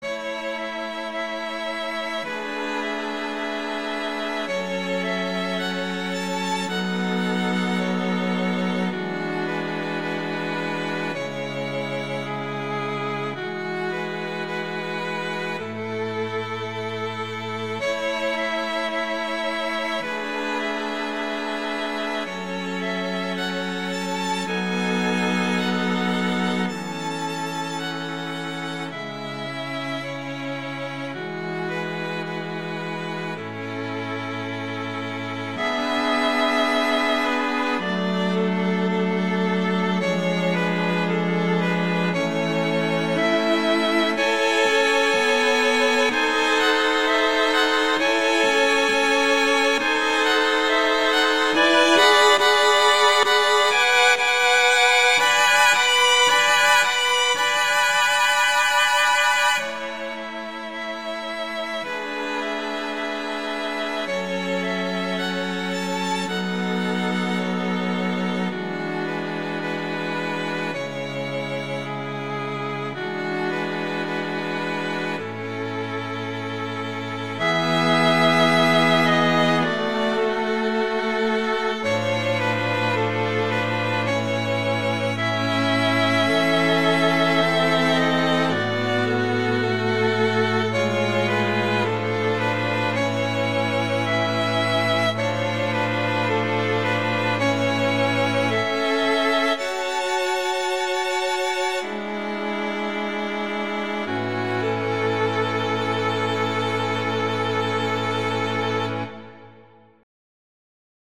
arrangement for string quartet
A major
♩=54 BPM